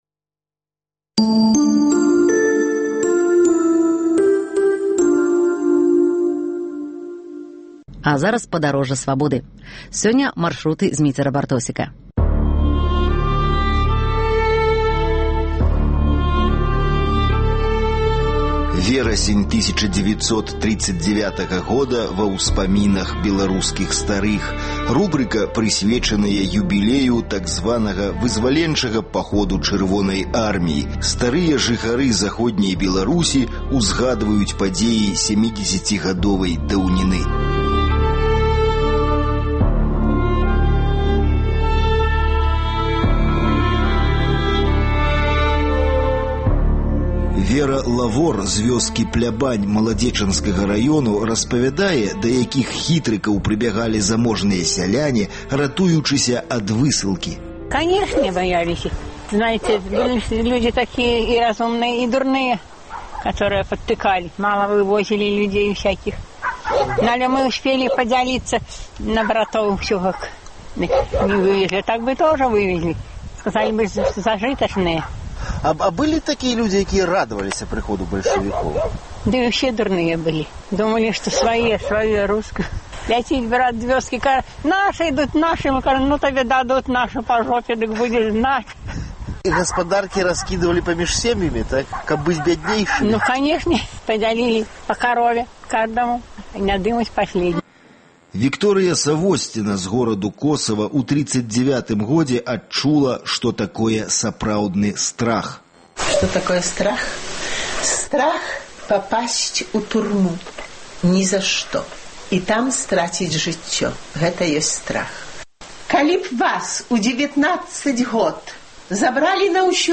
Штодня да 17 верасьня ў эфіры і на сайце "Свабоды" мы прапануем вашай увазе успаміны людзей пра верасень 1939 году, калі пачалася Другая ўсясьветная вайна і калі 17 верасьня савецкія войскі ўвайшлі ў Заходнюю Беларусь. Сёньня заключная перадача.